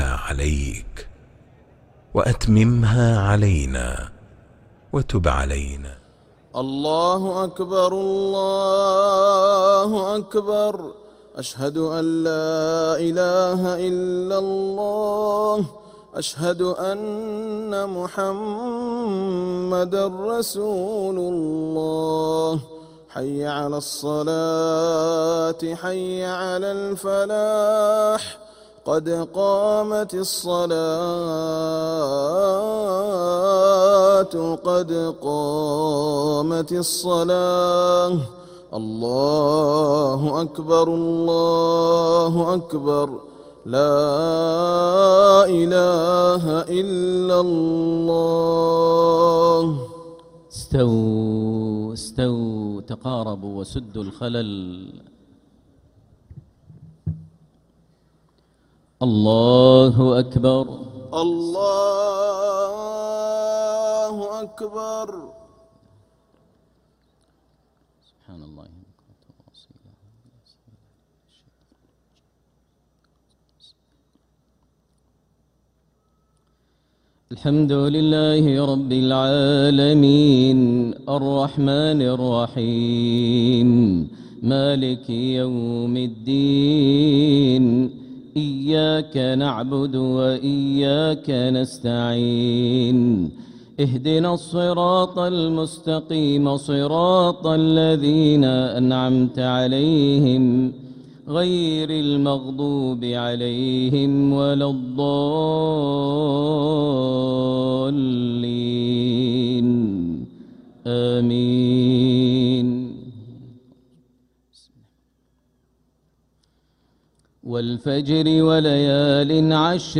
Makkah Maghrib - 19th April 2026